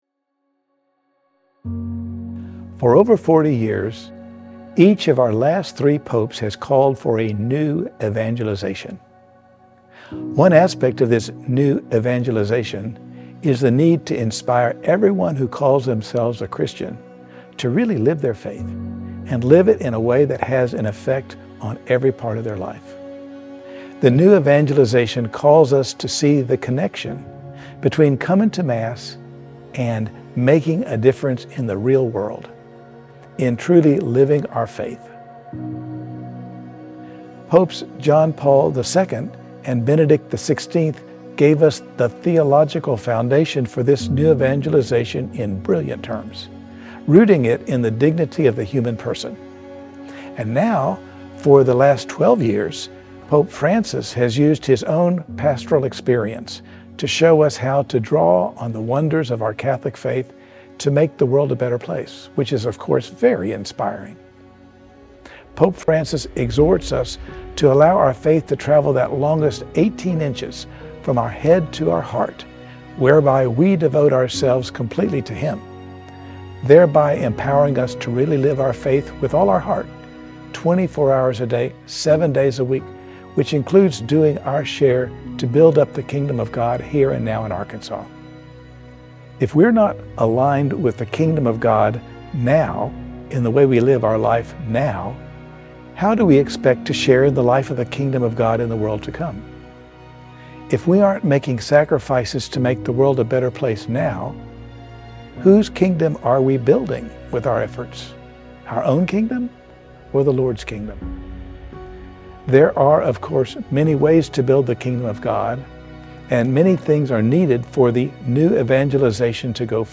Bishop Anthony B. Taylor recorded the following homily to be played at all Masses in Arkansas on Feb. 1-2, 2025, the feast of the Presentation of the Lord.